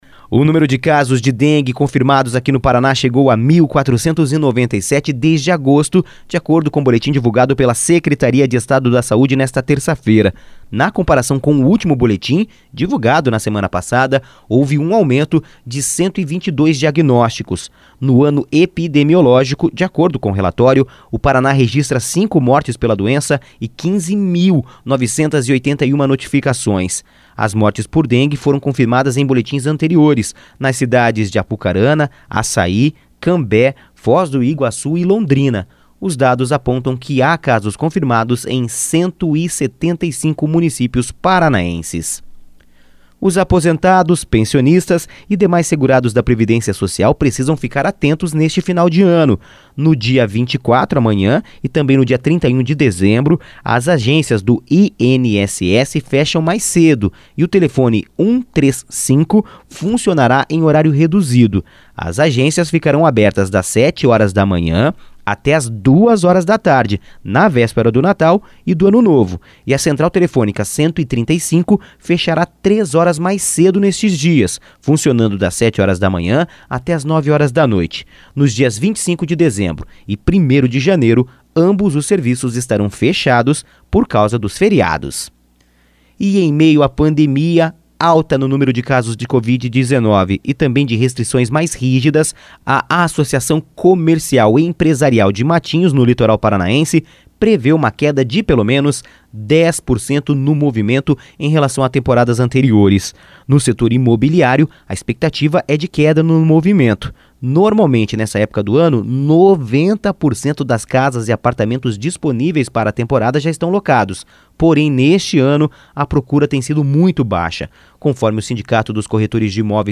Giro de Notícias Manhã SEM TRILHA